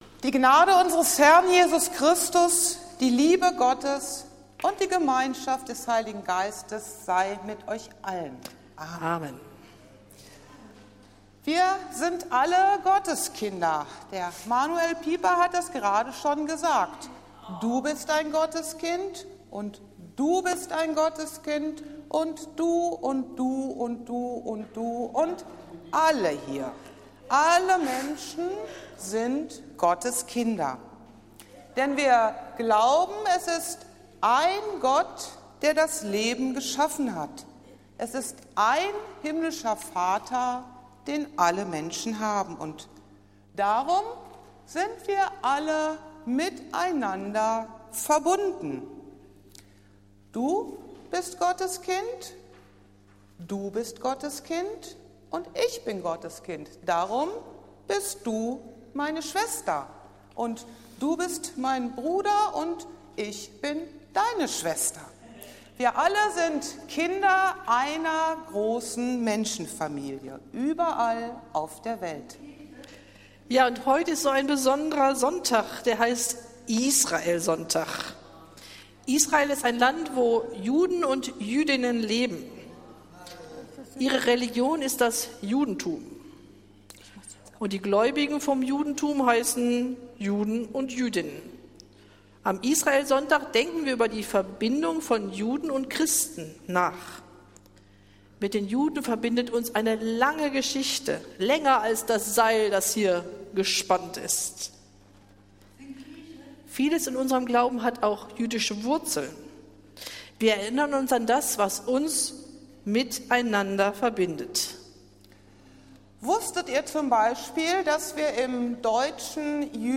Es handelte sich um einen Gottesdienst in einfacher Sprache.